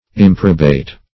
Search Result for " improbate" : The Collaborative International Dictionary of English v.0.48: Improbate \Im"pro*bate\, v. t. [L. improbatus, p. p. of improbare to disapprove; pref. im- not + probare to approve.] To disapprove of; to disallow.